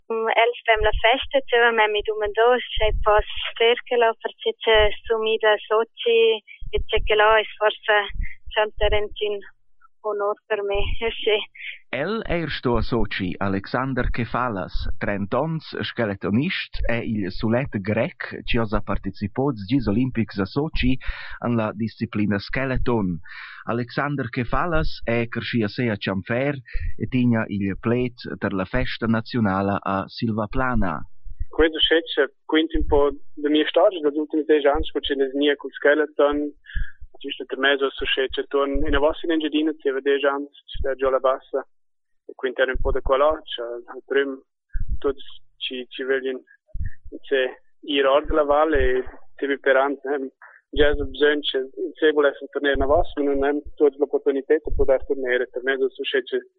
It sounds like it’s not the mother tongue of the people speaking.
The recording comes from Radiotelevisiun Svizra Rumantscha .